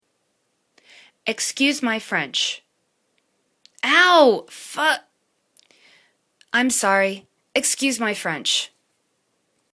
Ex.cuse my French     /ik'skyu:z mi: french/    spoken